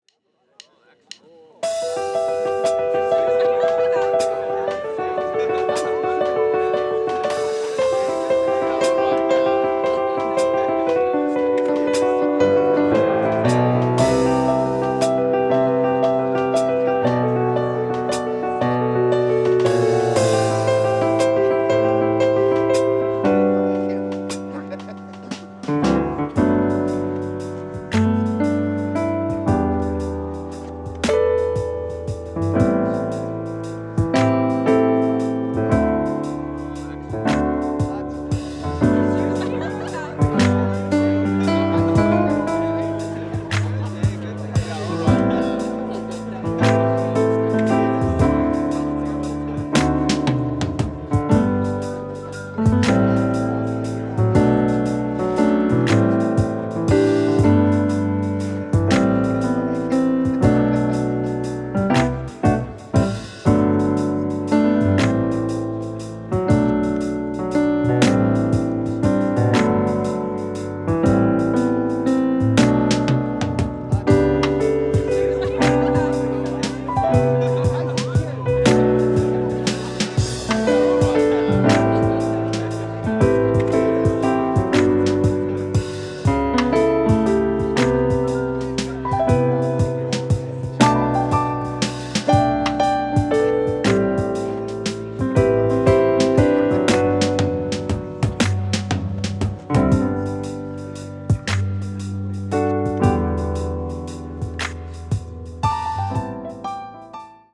BT Drums/Bass & Piano